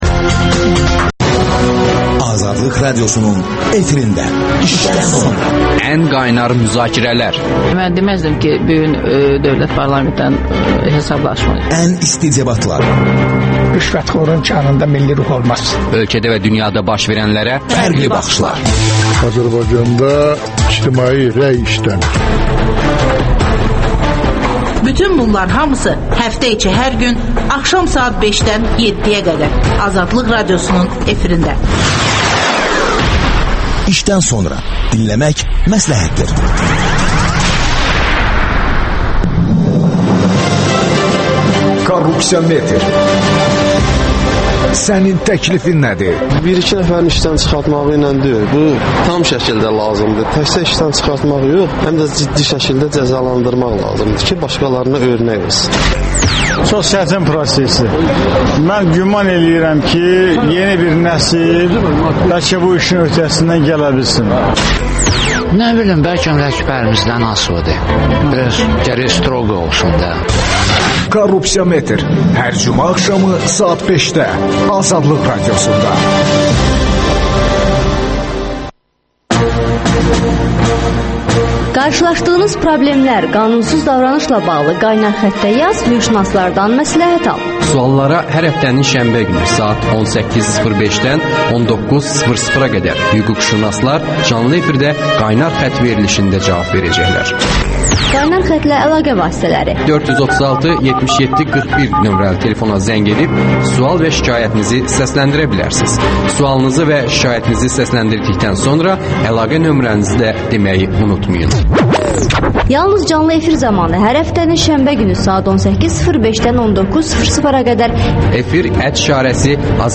İşdən sonra - AXCP sədri Əli Kərimli canlı efirdə…